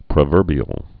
(prə-vûrbē-əl)